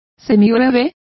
Complete with pronunciation of the translation of semibreve.